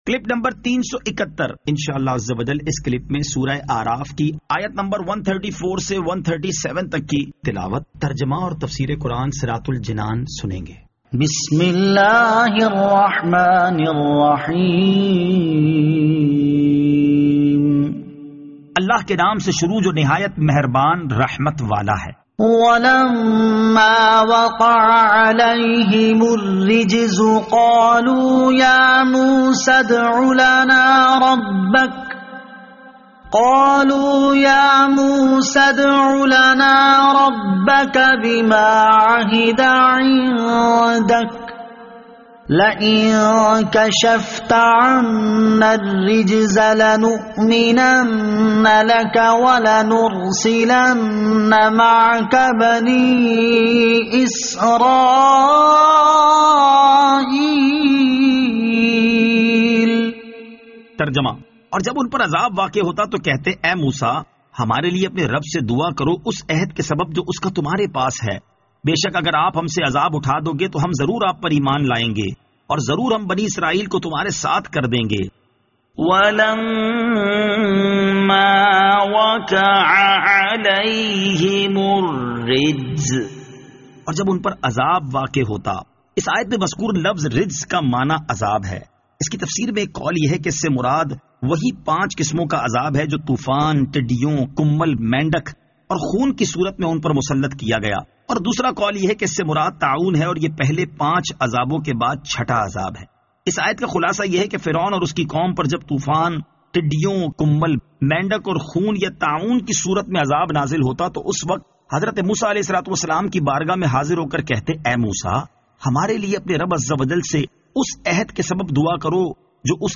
Surah Al-A'raf Ayat 134 To 137 Tilawat , Tarjama , Tafseer